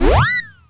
retro_jump_collect_bonus_01.wav